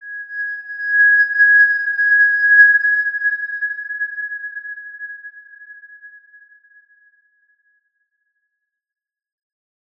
X_Windwistle-G#5-mf.wav